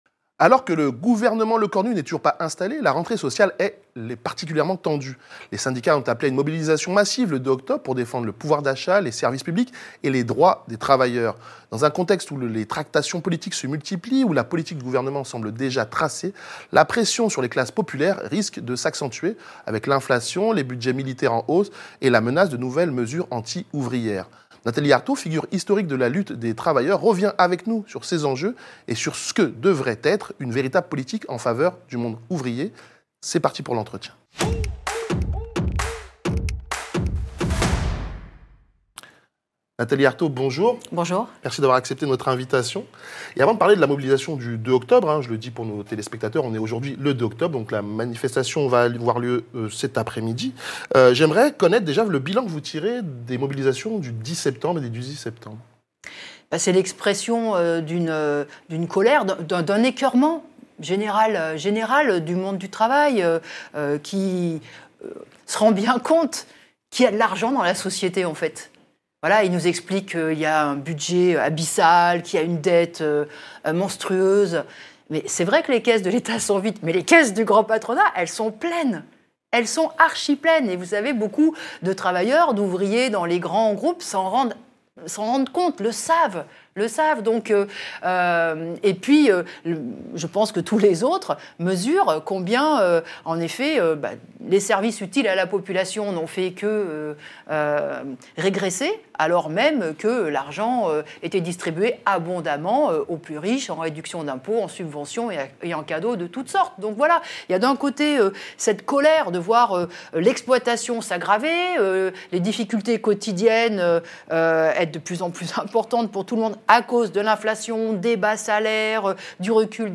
Le Media : Interview de Nathalie Arthaud, le 2 octobre 2025